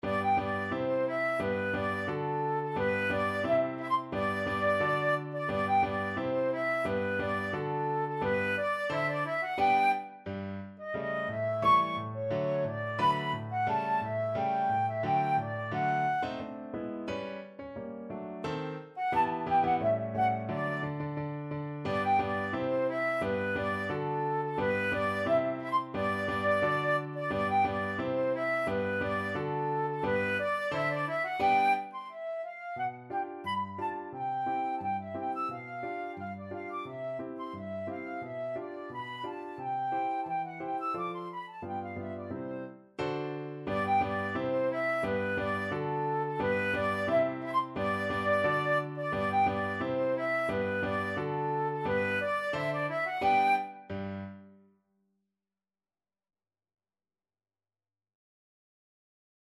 Classical Strauss II,Johann Fledermaus-Quadrille, Op.363 Flute version
Flute
~ = 88 Stately =c.88
G major (Sounding Pitch) (View more G major Music for Flute )
2/4 (View more 2/4 Music)
Classical (View more Classical Flute Music)